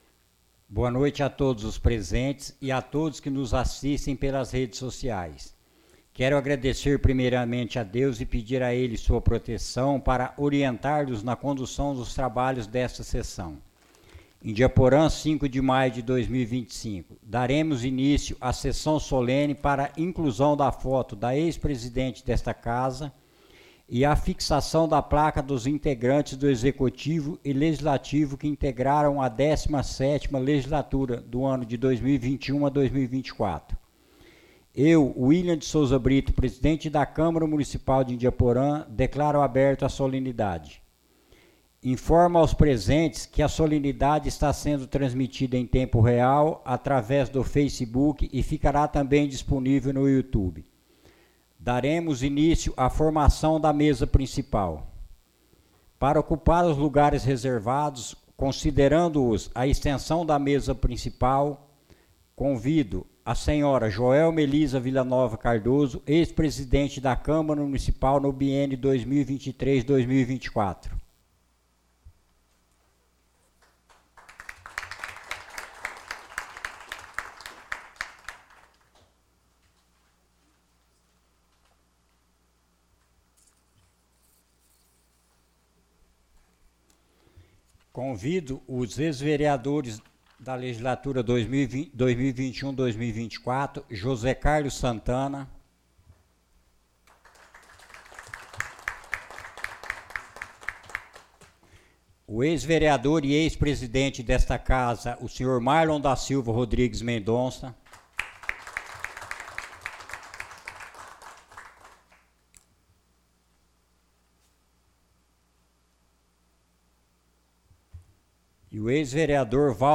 Solenidade Quadro 17ª legislatura 05.05.25.mp3 - áudio da sessão legislativa da Câmara Municipal de Indiaporã